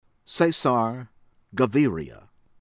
ESCOBAR, PABLO PAHB-loh    ehs-koh-BAHR